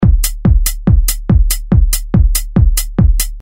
保持调频混乱的合成器A Sharp 142
描述：一个有趣的调频合成器。FM是指频率调制。非常奇怪的声音。可能适合于恍惚、滑稽、电子乐或类似的东西。
标签： 142 bpm Trance Loops Synth Loops 582.35 KB wav Key : A
声道立体声